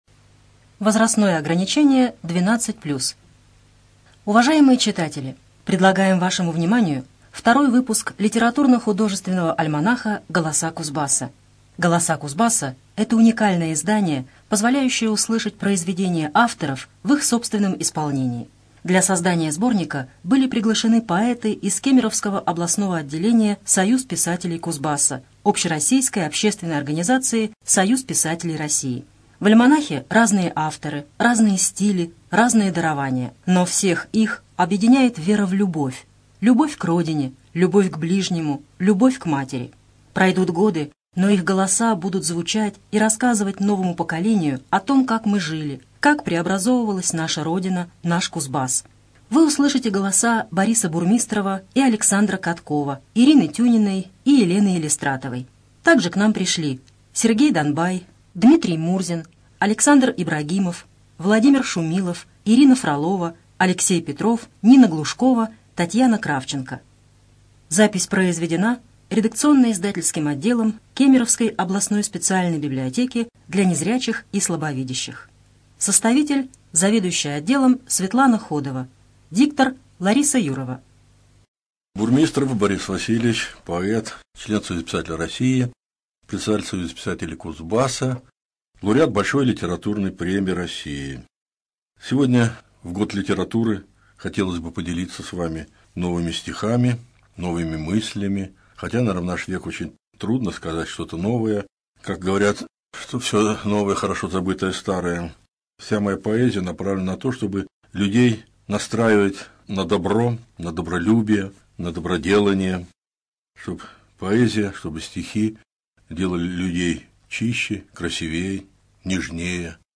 ЖанрПоэзия
Студия звукозаписиКемеровская областная специальная библиотека для незрячих и слабовидящих